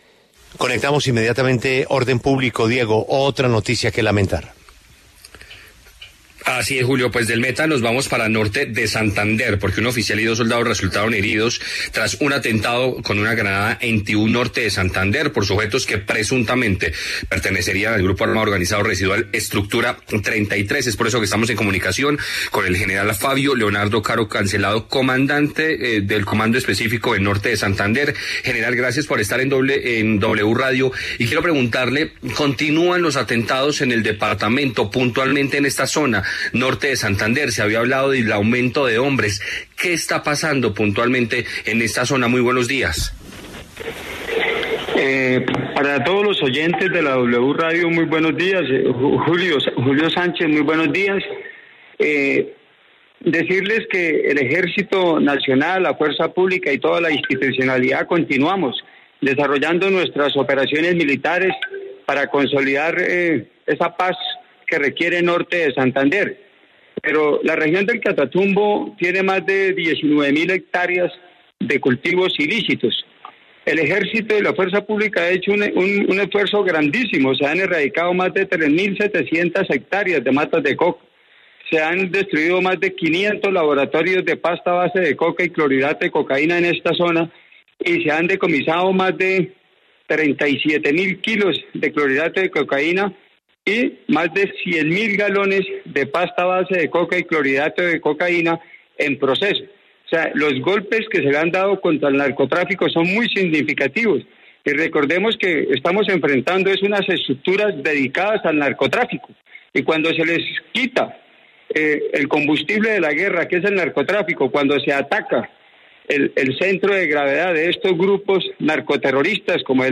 En La W, el general Fabio Leonardo Caro se refirió a los esfuerzos de la fuerza pública por combatir el narcotráfico en Norte de Santander.